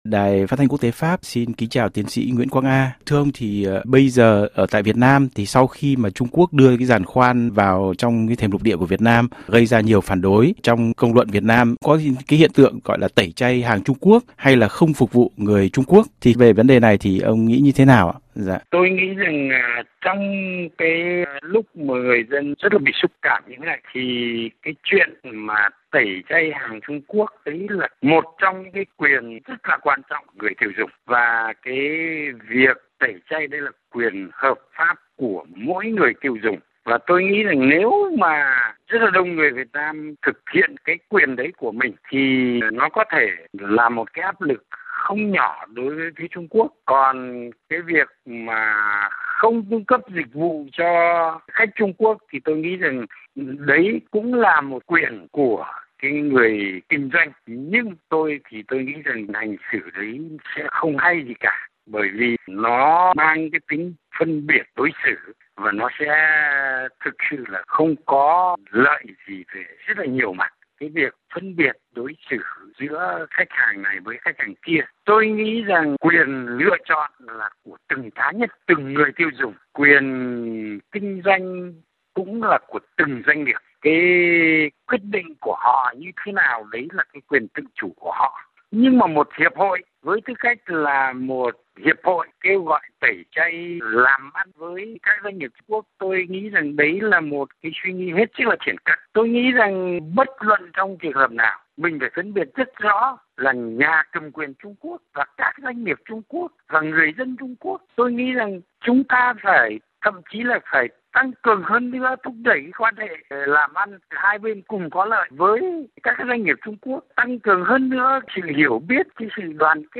Trích đoạn phỏng vấn